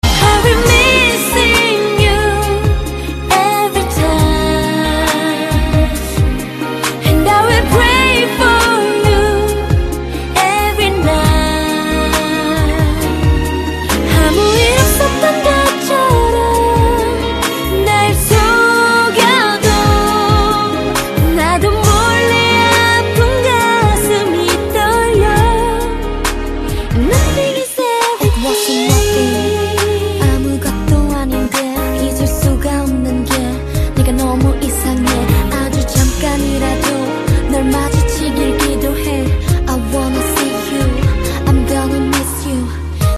分类: MP3铃声